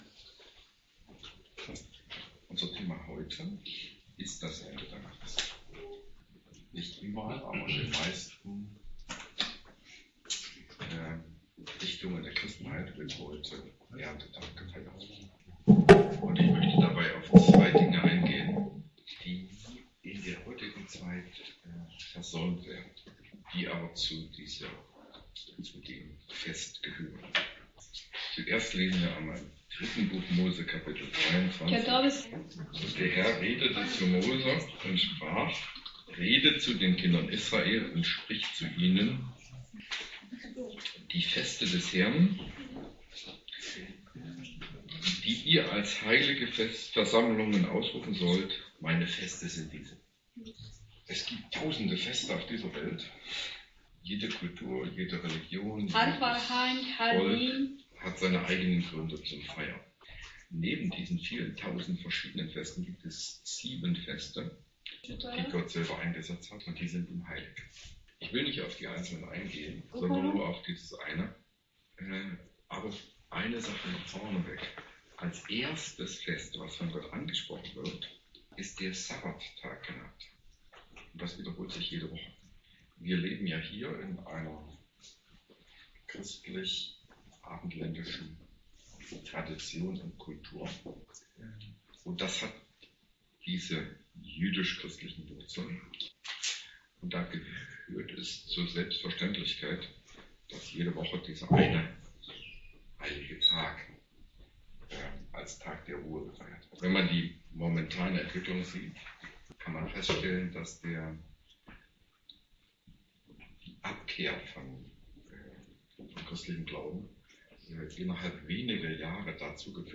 3.Mose 23 | Predigt zum Thema: Erntedankfest - Europäische Missionsgemeinschaft